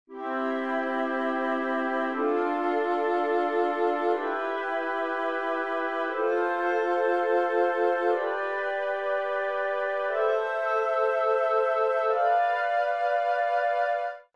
Sulla scala di Do maggiore otterremo questa serie di accordi:
accordi_scala_Do.mp3